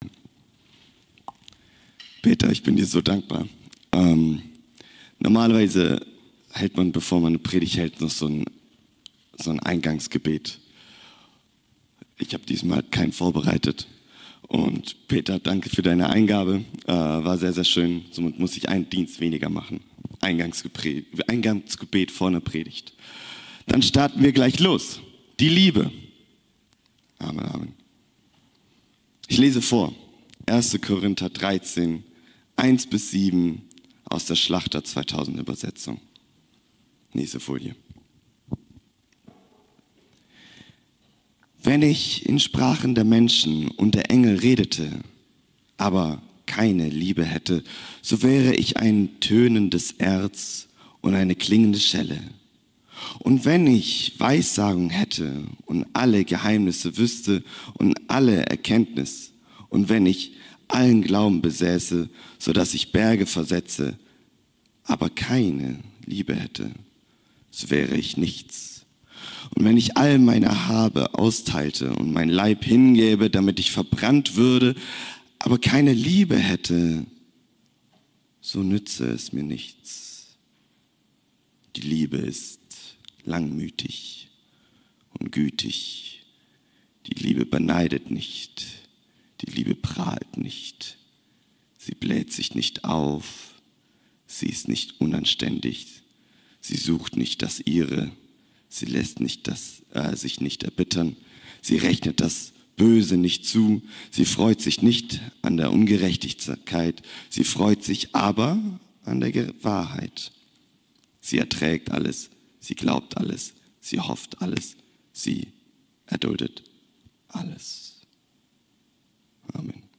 Predigten Dezember 2024